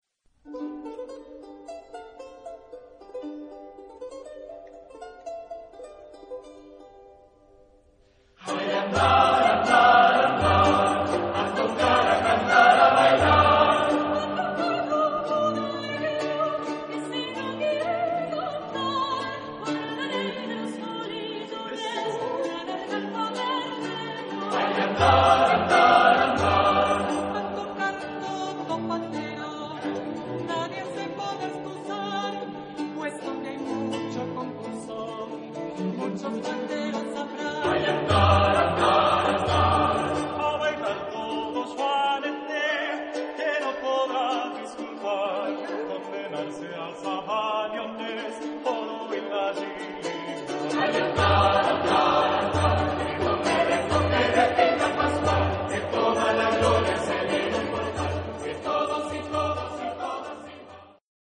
... les 3 voix solistes ne chantent que dans la 2°partie du "Coplas" ...
Género/Estilo/Forma: Villancico ; Sagrado
Tipo de formación coral: SSAT  (4 voces Coro mixto )
Solistas : Soprano (1) / Alto (1) / Ténor (1)  (3 solista(s) )
Instrumentos: Bajo Continuo
Tonalidad : sol mayor